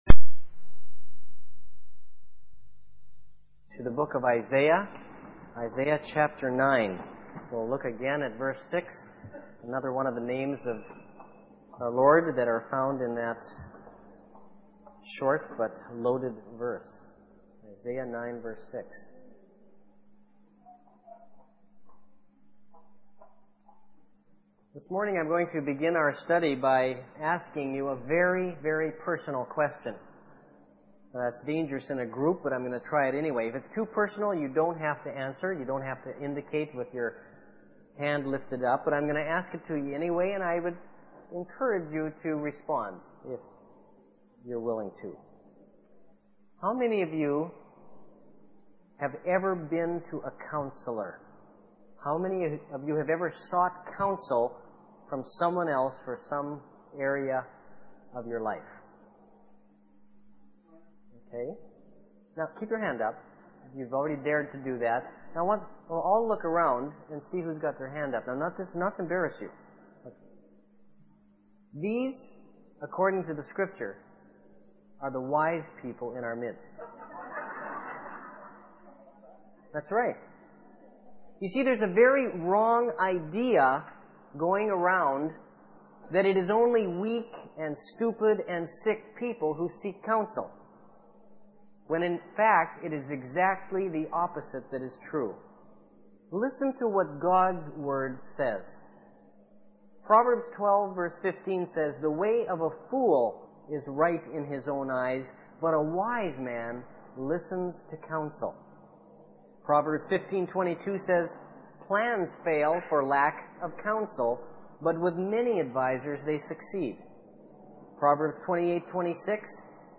1998 Counselor Preacher